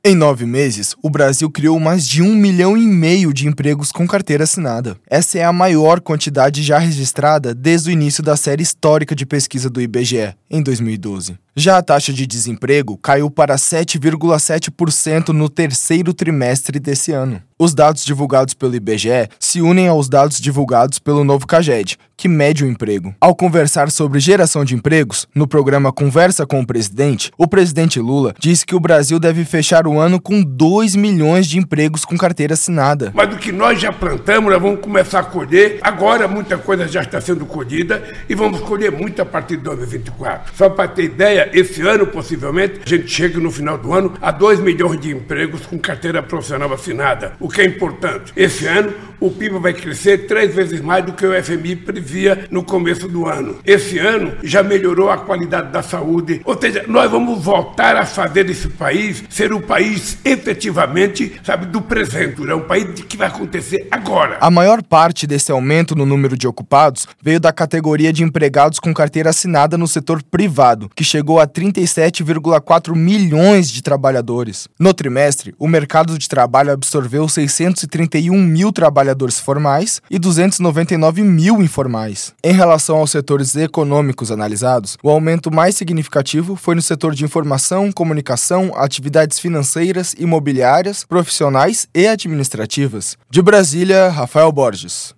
Boletins de Rádio